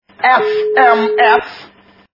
» Звуки » звуки для СМС » Мужской голос - Эс-Эм-Эс
При прослушивании Мужской голос - Эс-Эм-Эс качество понижено и присутствуют гудки.
Звук Мужской голос - Эс-Эм-Эс